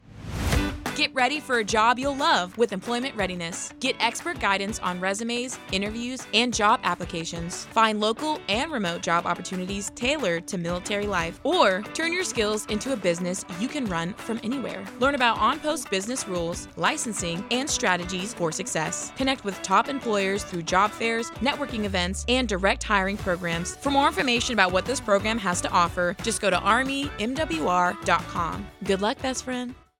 AFN Humphreys Radio SPOT: Employment Readiness Program (ERP)
A 30-second spot about Employment Readiness. The Employment Readiness Program (ERP) provides resources to support your job search.